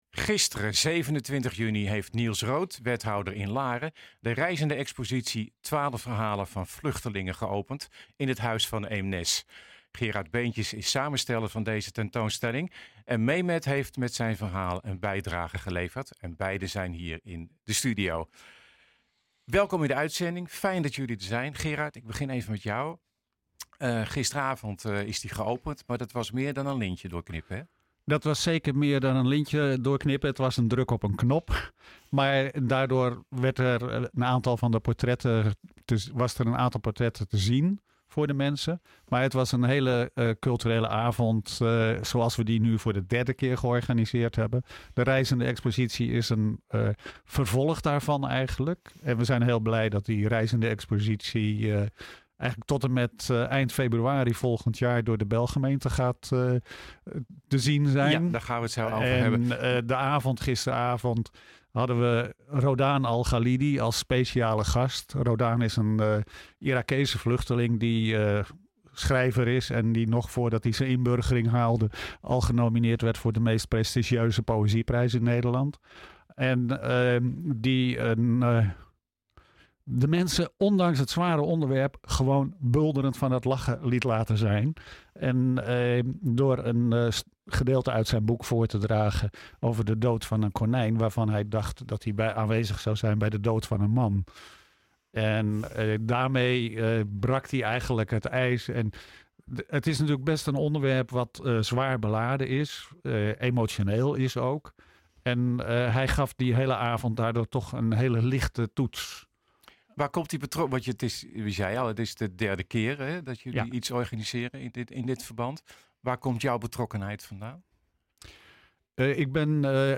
Beiden zijn hier in de studio.